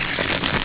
Bats
BATS.wav